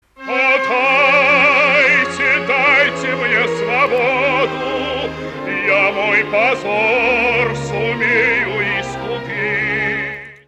Кто поёт?